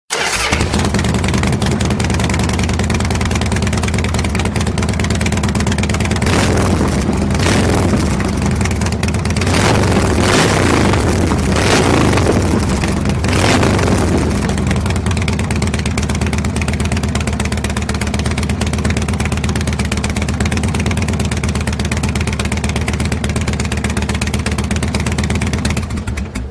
harley-engine-sound_24779.mp3